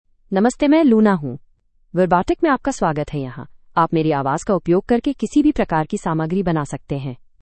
LunaFemale Hindi AI voice
Luna is a female AI voice for Hindi (India).
Voice sample
Listen to Luna's female Hindi voice.
Luna delivers clear pronunciation with authentic India Hindi intonation, making your content sound professionally produced.